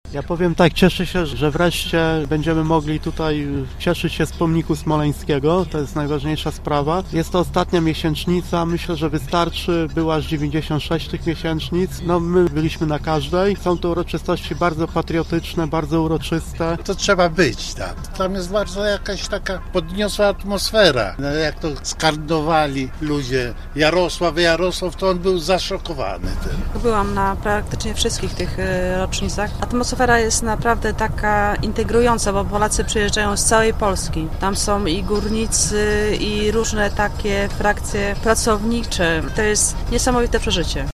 Dla nas dzisiejsze wydarzenie to sprawa bardzo ważna – mówią mieszkańcy, z którymi przed wyjazdem rozmawialiśmy.